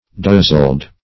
dozzled - definition of dozzled - synonyms, pronunciation, spelling from Free Dictionary Search Result for " dozzled" : The Collaborative International Dictionary of English v.0.48: Dozzled \Doz"zled\, a. [[root]71.]